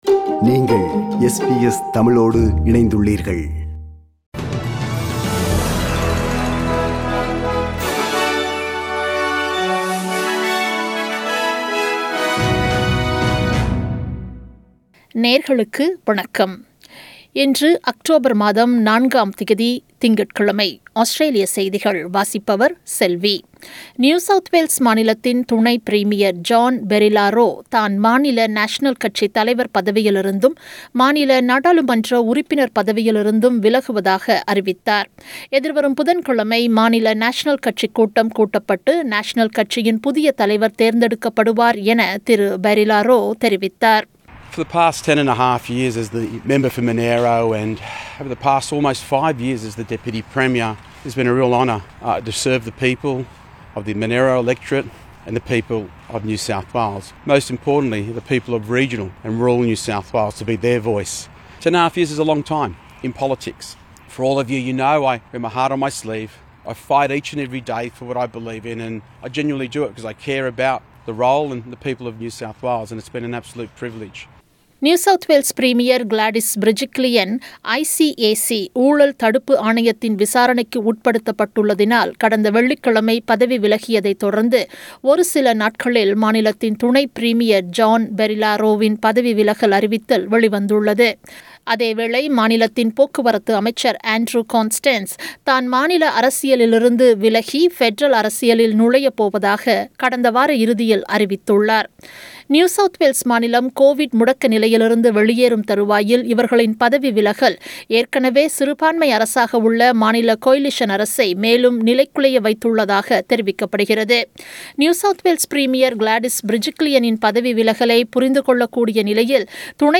Australian News: 04 October 2021 – Monday